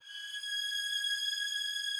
strings_080.wav